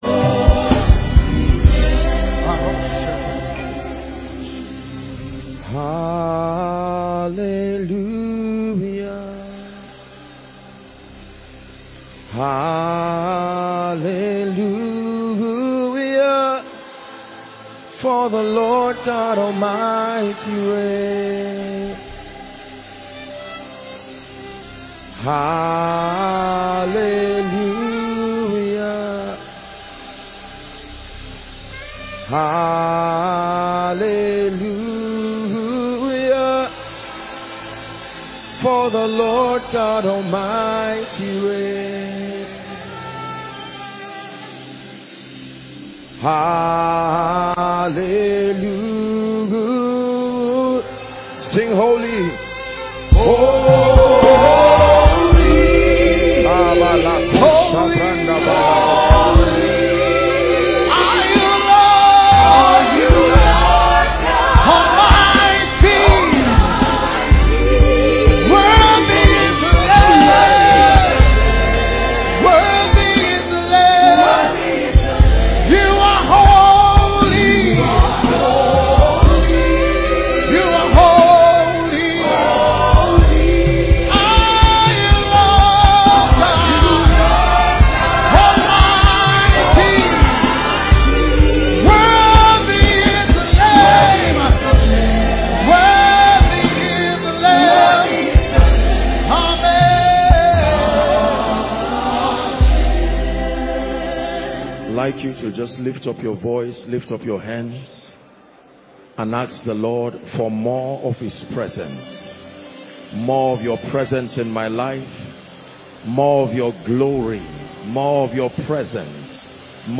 In this sermon Testimony of Enoch